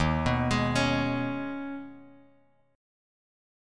D#7sus4#5 Chord